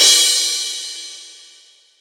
CYMBAL_3001.WAV